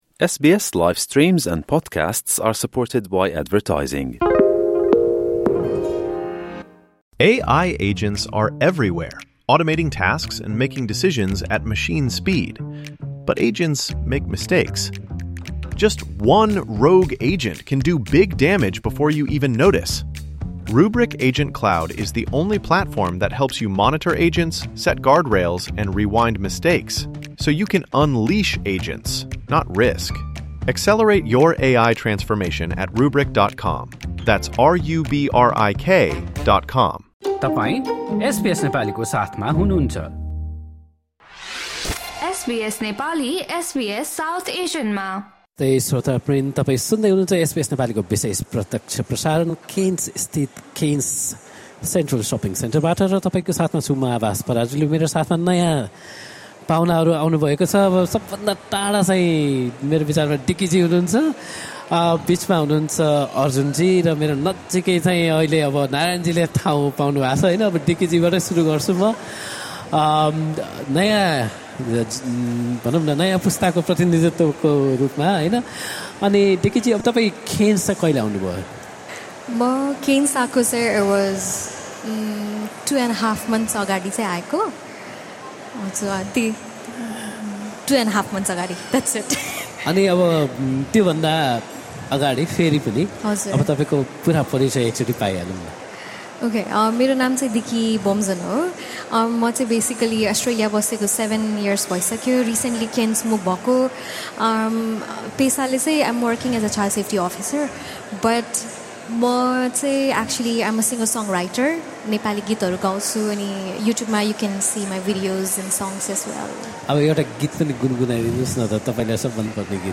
एसबीएस नेपाली लगायत तिहार तथा दीपावली मनाउने एसबीएस साउथ एसियनका भाषा सेवाहरूले क्वीन्सल्यान्डको राजधानी ब्रिसबेनबाट झन्डै १,४०० किलोमिटर उत्तरमा पर्ने क्षेत्रीय शहर केर्न्सबाट बिहीबार, अक्टोबर ९ मा आफ्ना रेडियो कार्यक्रमहरूको प्रत्यक्ष प्रसारण गरेका थिए। दिनभर केर्न्स स्थित केर्न्स सेन्ट्रल सपिङ सेन्टरबाट प्रत्यक्ष प्रसारणका क्रममा केही नेपालीभाषीहरू पाहुनाका रूपमा एसबीएस नेपालीको रेडियो कार्यक्रममा जोडिनुभएको थियो।